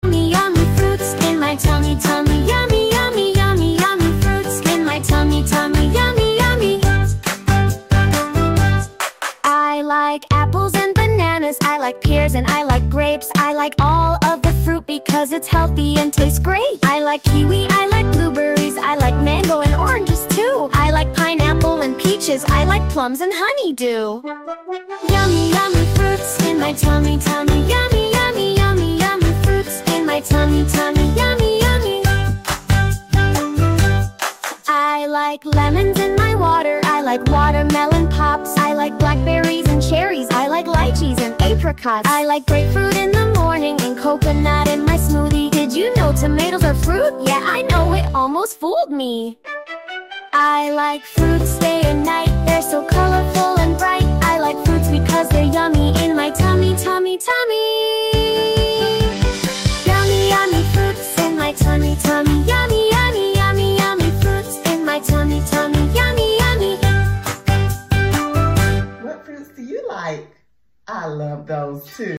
Identify the different fruits and name them with the fun “Yummy, Yummy Fruits” song. This video also introduces sign language with the letter ‘F’ in sign language and the word ‘fruit’.